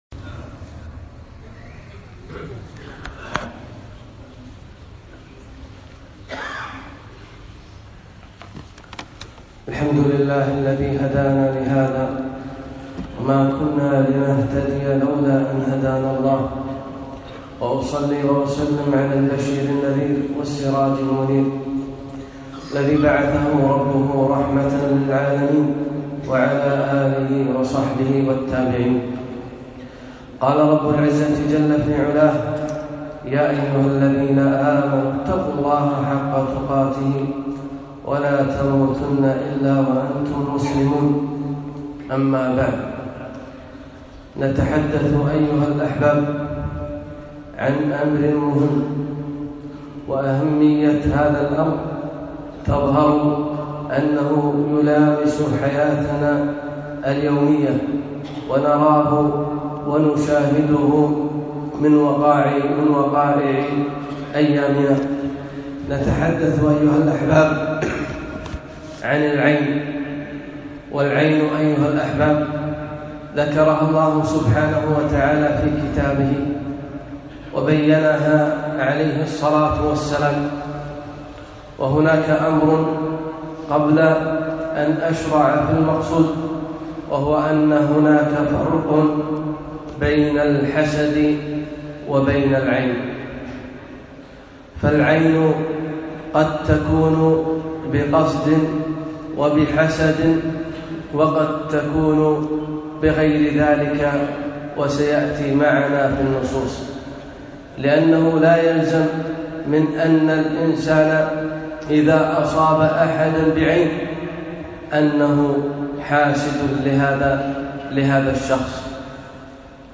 خطبة - العين